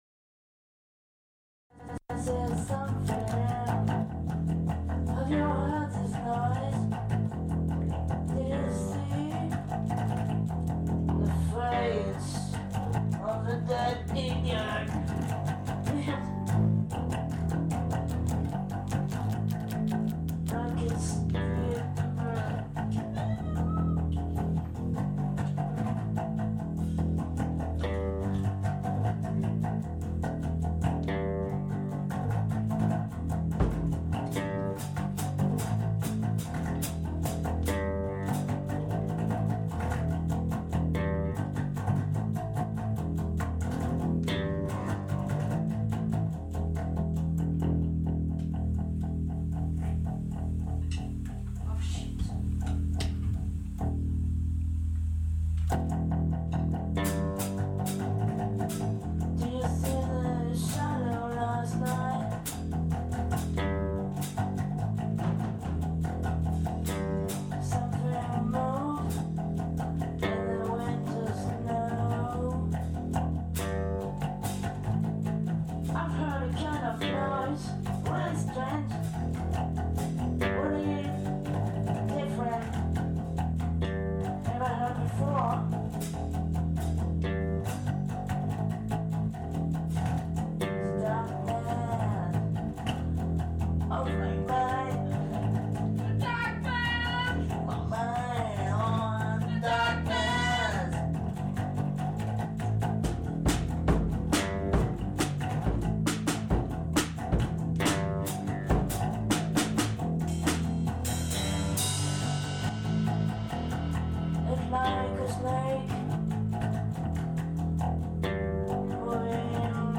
Mastofuries est le projet secret d'un groupe éphémère de deux albums enregistrés pendant deux nuits en 2002.